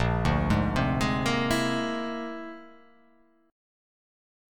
A#9b5 chord